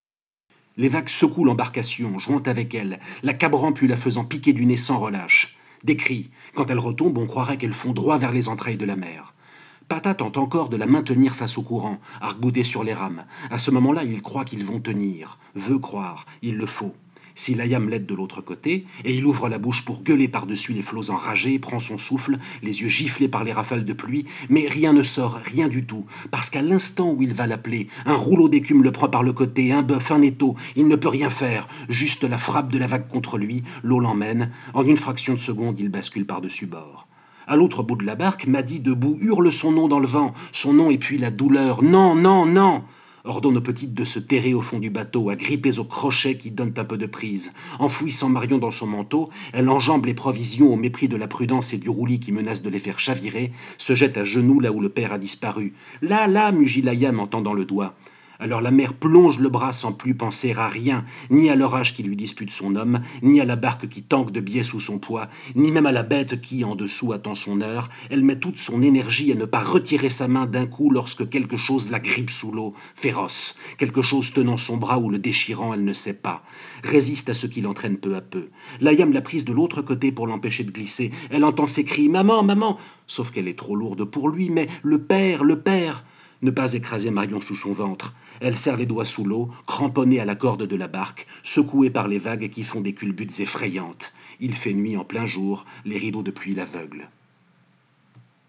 Pour l’occasion, et parce que j’ai vraiment aimé ce que j’ai lu, plutôt que juste en reproduire les photos j’ai choisi de vous lire les pages en question :